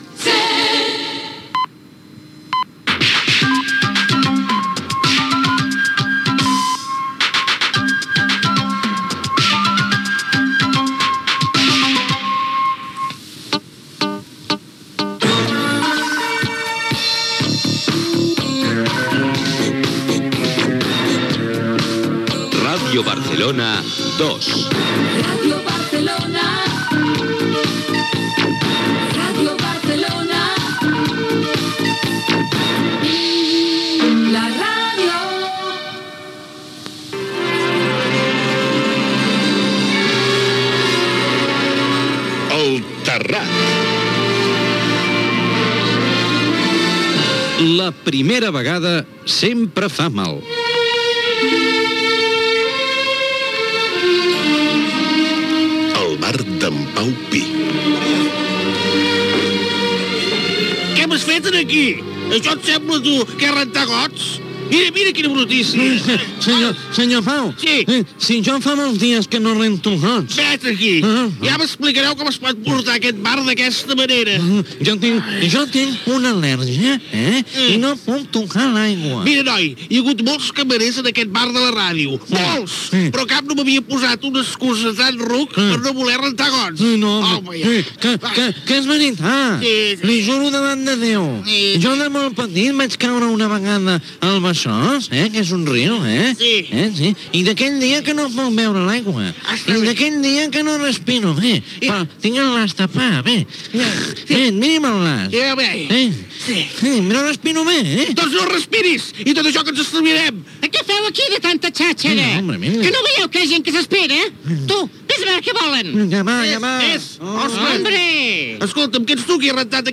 077a70c4ab98305e5073f917b16ddc0af12fedb7.mp3 Títol Ràdio Barcelona 2 Emissora Ràdio Barcelona 2 Cadena SER Titularitat Privada estatal Nom programa El terrat Descripció Indicatius de la cadena i de l'emissora. Careta del programa.